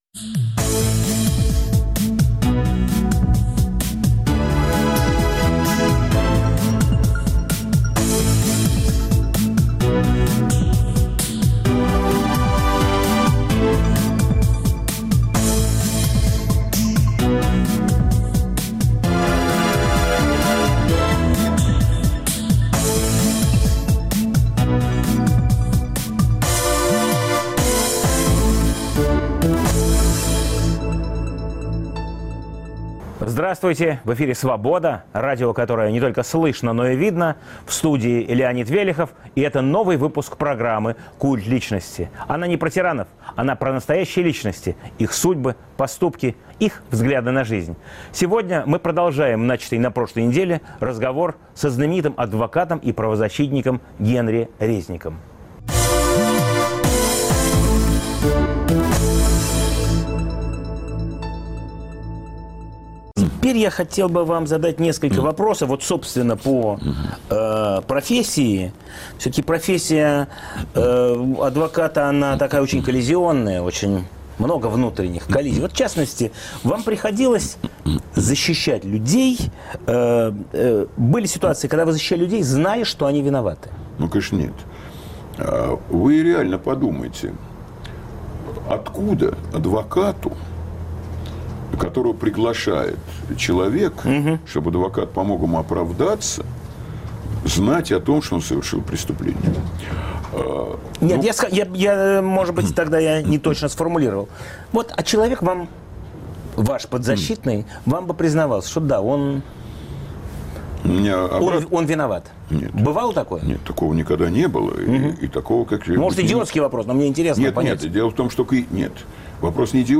Новый выпуск программы о настоящих личностях, их судьбах, поступках и взглядах на жизнь. В студии адвокат Генри Резник.